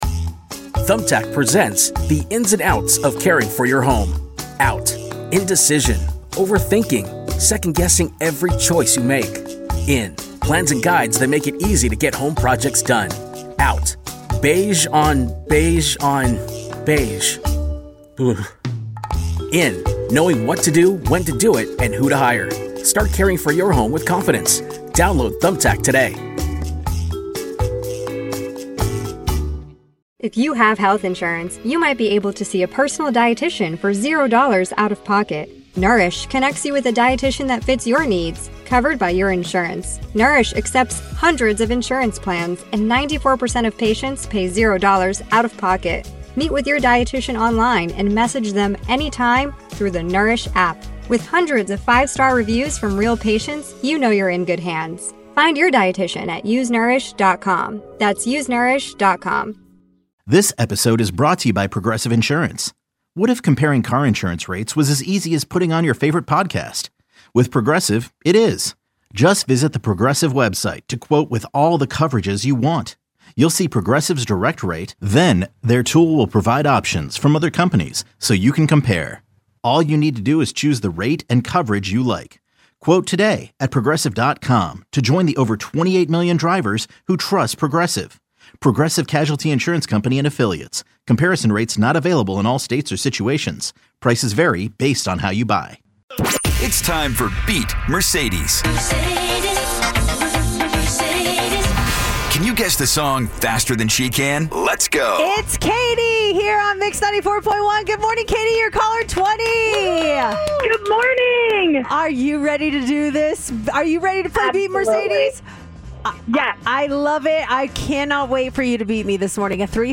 Three local, Las Vegas friends discuss life, current events, and everything else that pops into their heads.
Genres: Comedy, Music